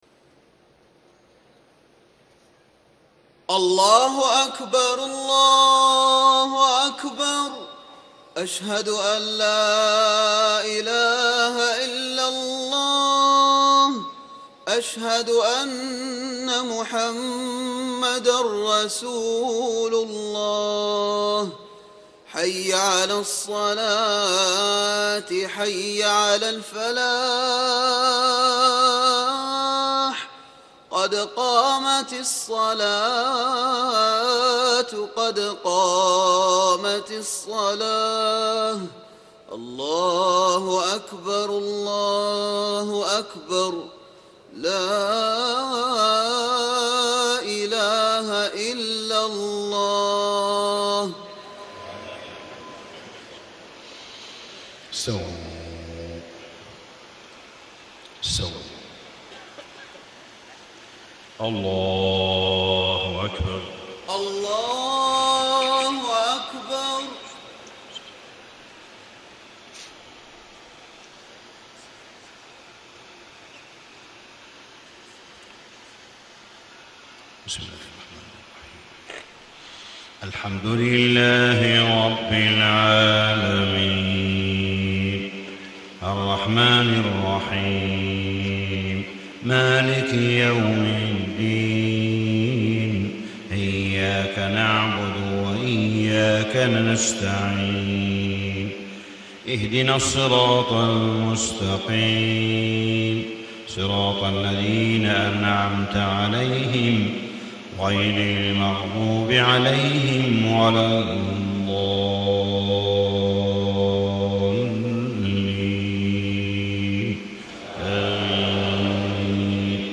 فجر 12 رمضان ١٤٣٥ من سورة القصص > 1435 🕋 > الفروض - تلاوات الحرمين